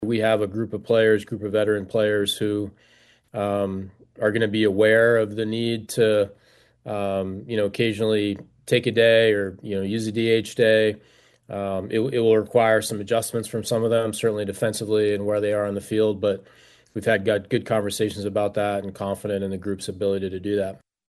Kelly and Cherington spoke on a Grapefruit League conference call and both addressed the Andrew McCutchen situation.